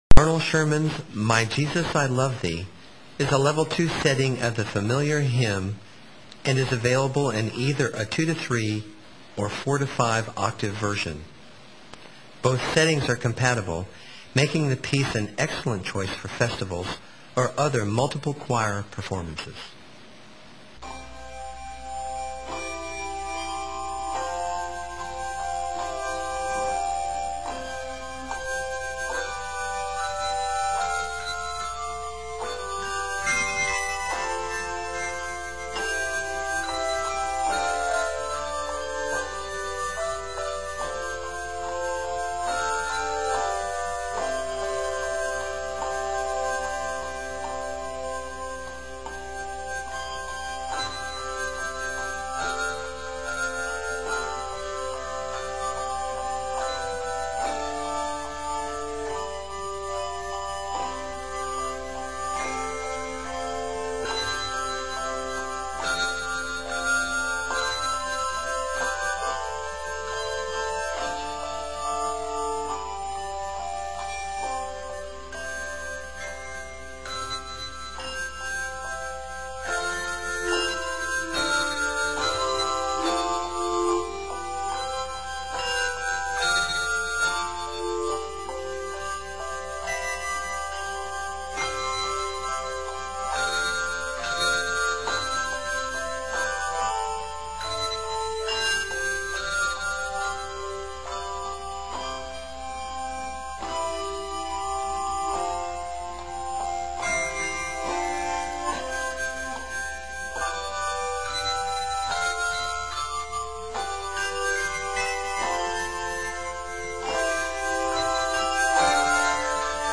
The arranger of this gospel hymn tune writes
Arranged for 2-3 octaves and 4-5 octaves of handbells.